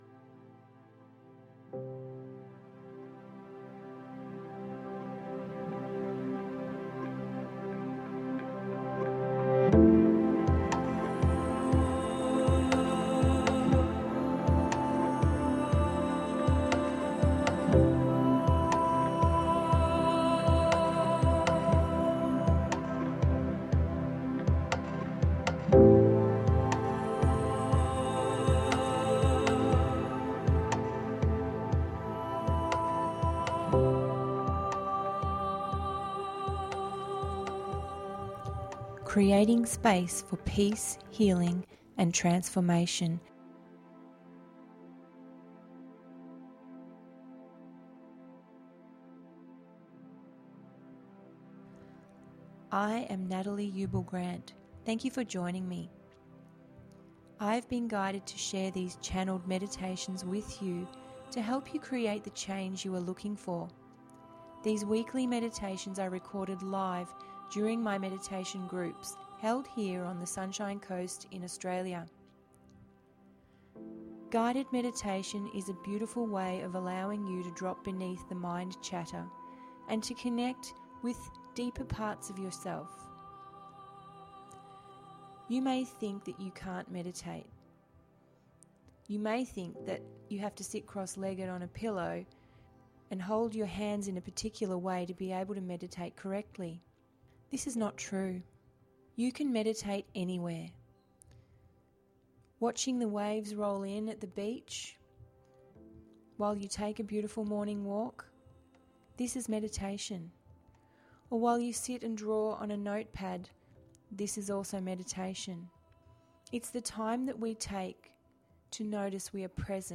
A simple relaxing guided meditation with guidance and messages from within. After arriving at your destination, immerse yourself in the water of the lake as you cleanse and release emotions that arise.